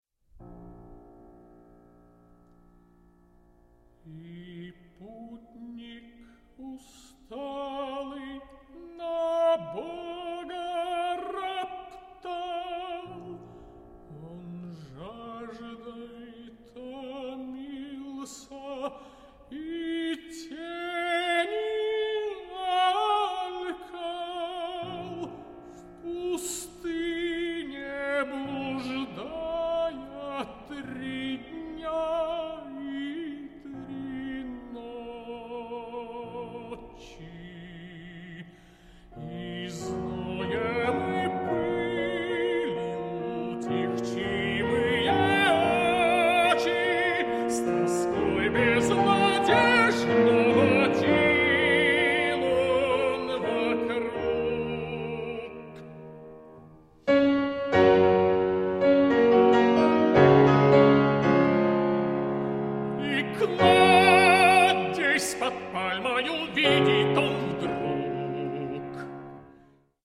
tenor
piano